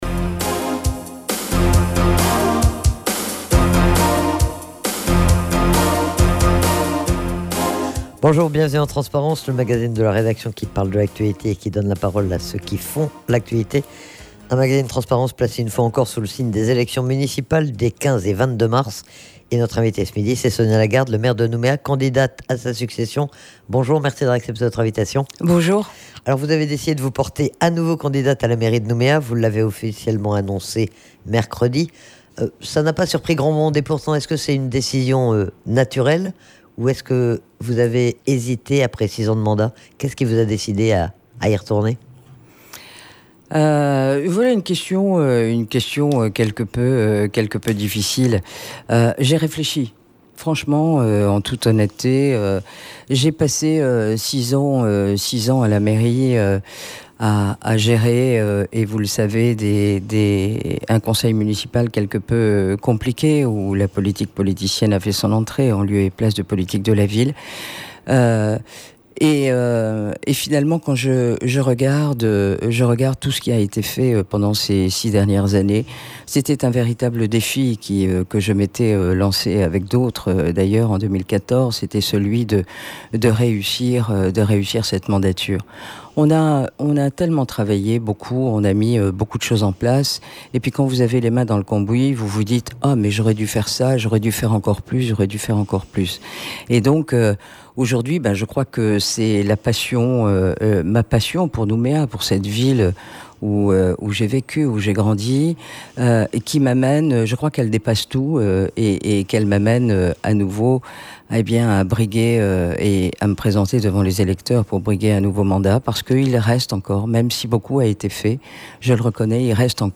Sonia Lagarde est interrogée sur ses motivations, sur son bilan, sur son programme mais aussi sur l'équipe qu'elle a constituée en vue des municipales des 15 et 22 mars.